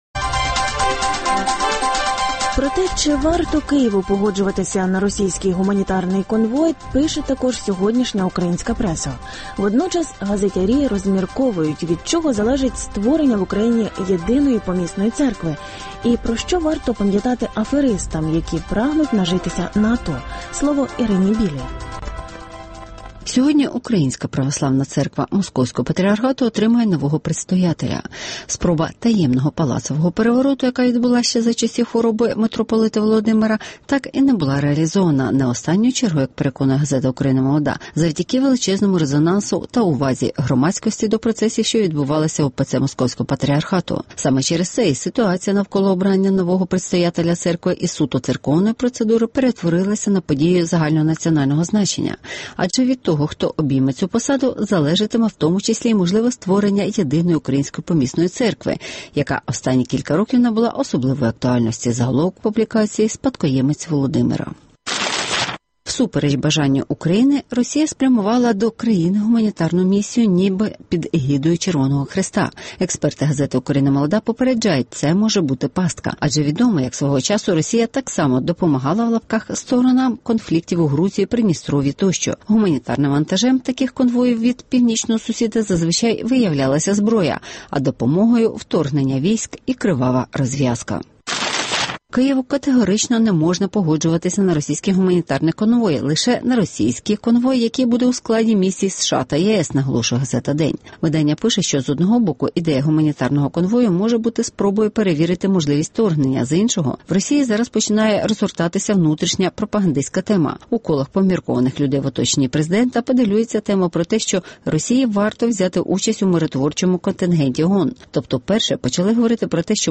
«Троянський кінь» від Путіна (огляд преси)